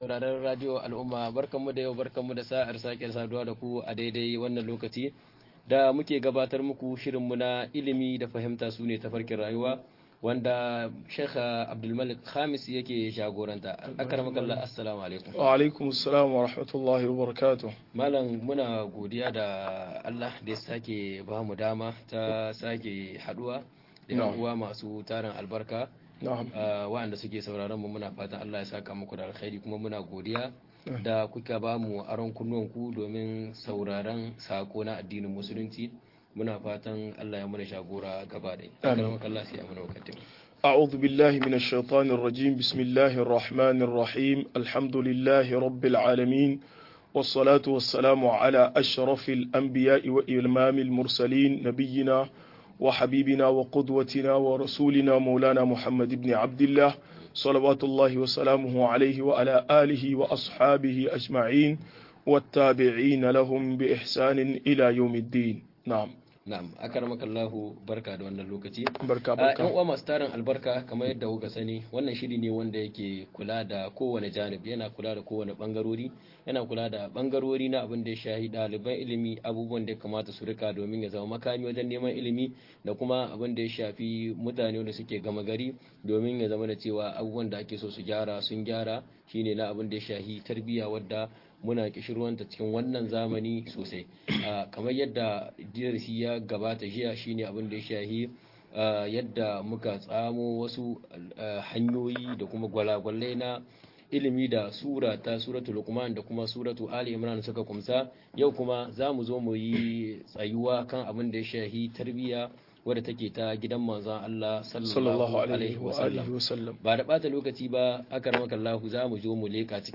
Tarbiyya daga Suratu ahzaab - MUHADARA